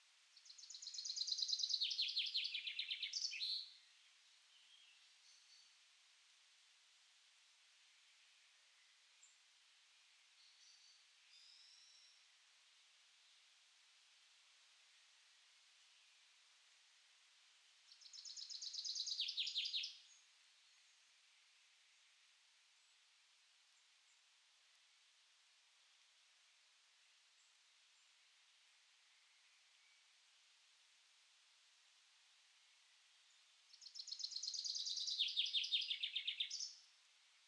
vtáčik.mp3